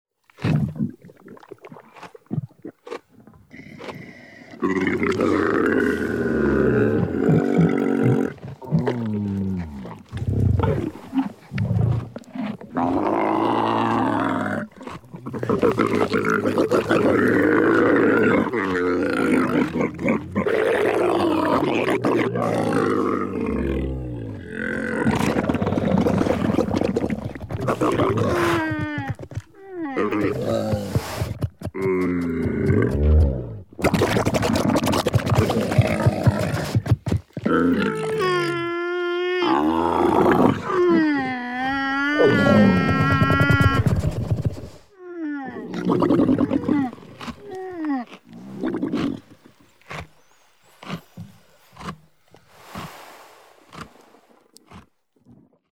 Звуки верблюдов: Стая остановилась в пустыне перекусить и отдохнуть (сборник звуков группы)